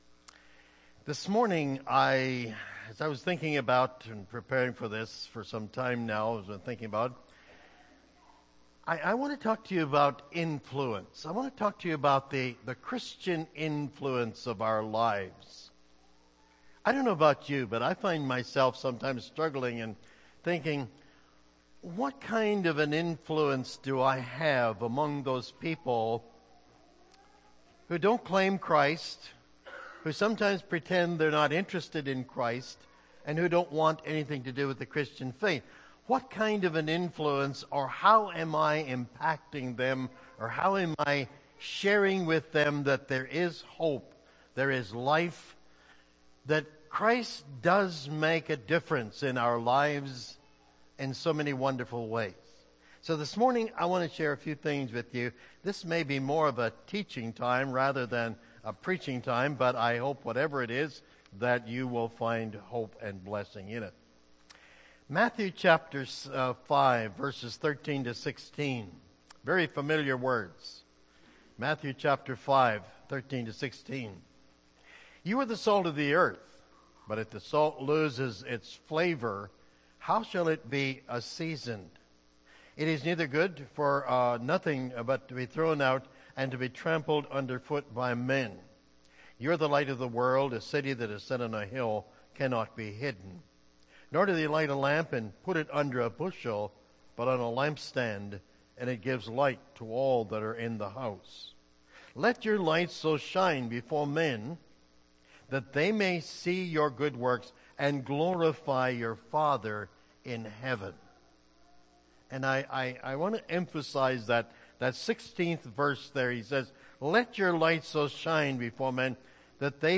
Dec. 29, 2013 – Sermon